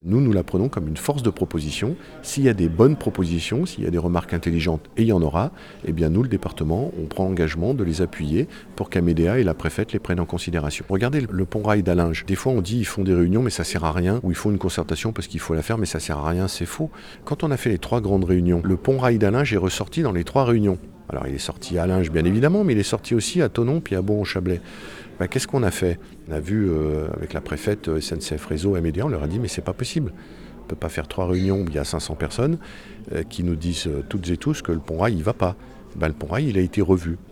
Cette période de consultation publique est justement une opportunité pour les habitants du territoire de faire entendre leurs avis comme l’explique Martial Saddier, président du conseil départemental.